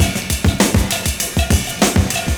100PERCS01.wav